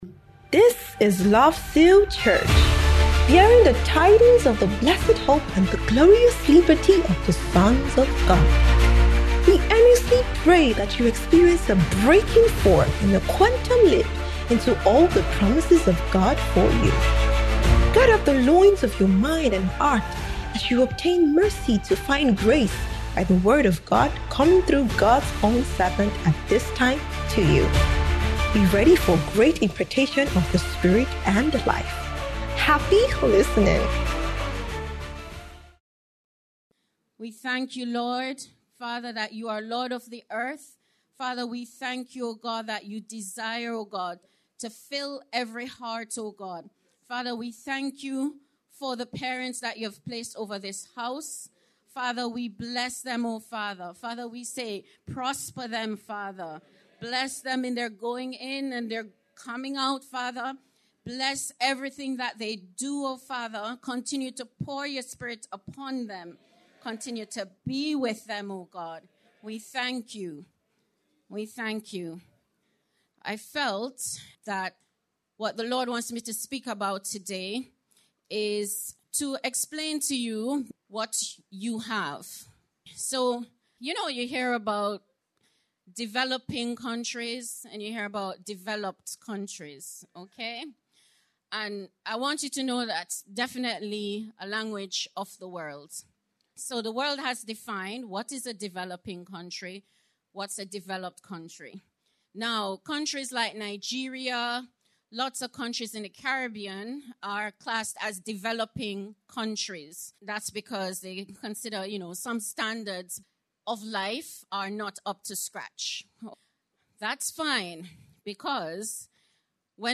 SUNDAY APOSTOLIC BREAKTHROUGH IMPARTATION SERVICE